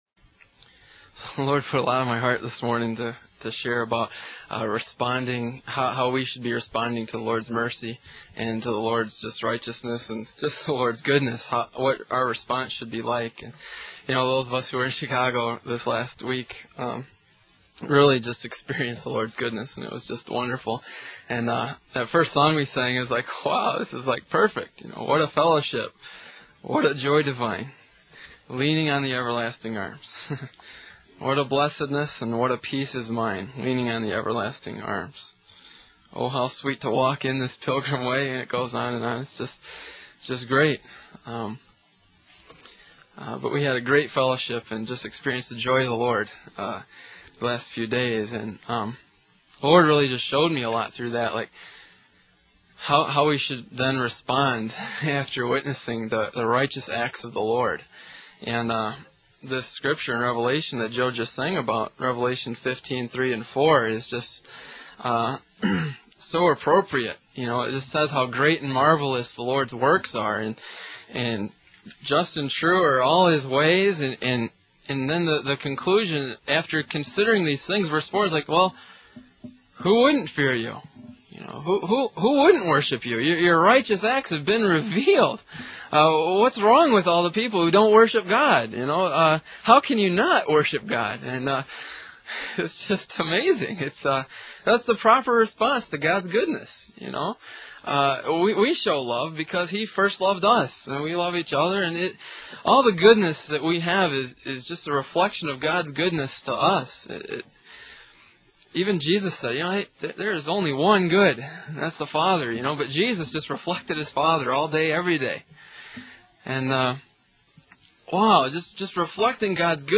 In this sermon, the preacher emphasizes the greatness and marvelous works of the Lord, as mentioned in Revelation 15:3-4. The sermon then focuses on the proper response to God's goodness, highlighting the importance of worshiping and fearing Him.